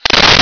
Sfx Holo Off
sfx_holo_off.wav